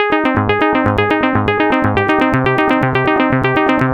Niagra Falls Ab 122.wav